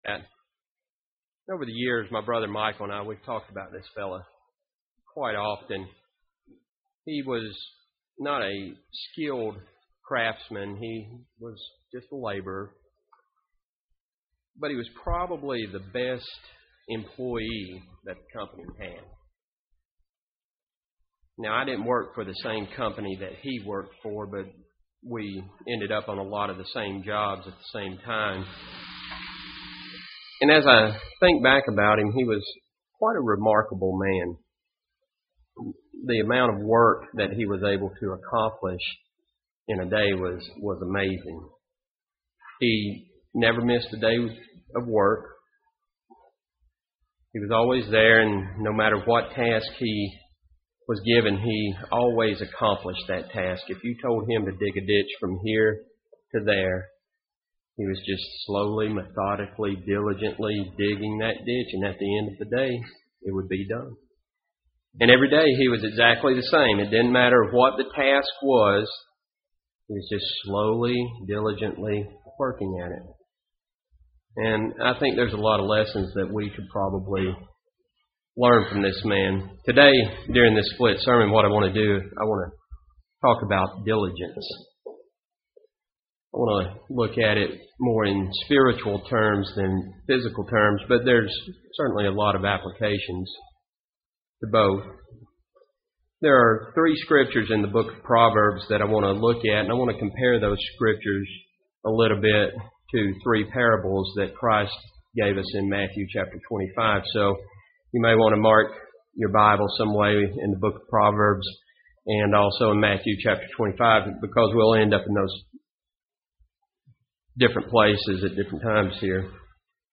Many people are extremely diligent about career, hobbies or family but how diligent are we spiritually? This sermon takes a look at Spiritual Diligence.